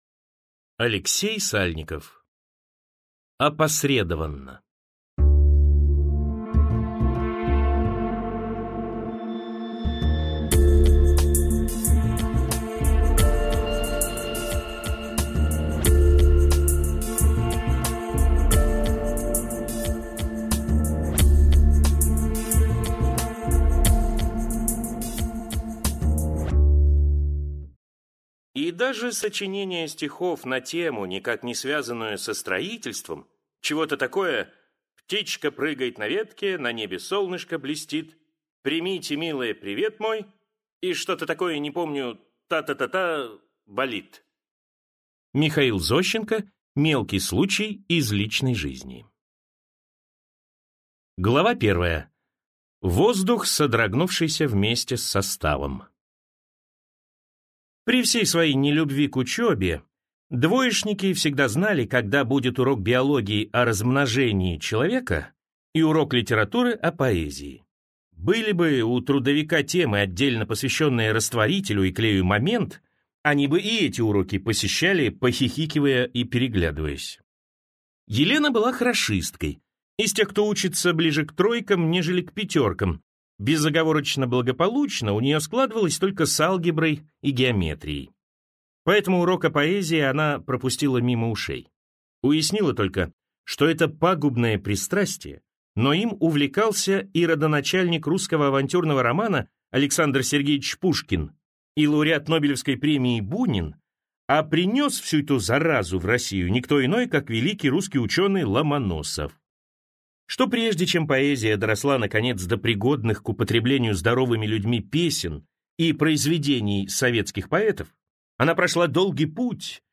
Аудиокнига «Опосредованно» Алексей Сальников в интернет-магазине КнигоПоиск ✅ в аудиоформате ✅ Скачать Опосредованно в mp3 или слушать онлайн